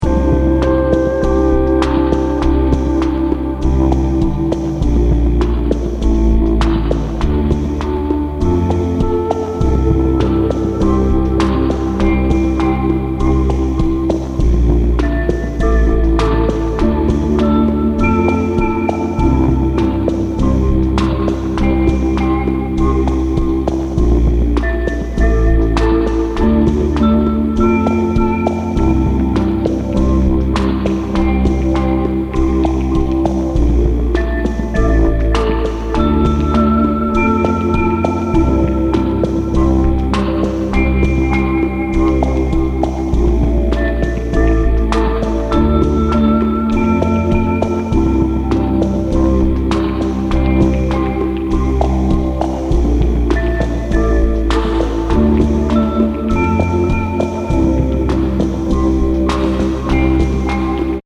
Electronix Ambient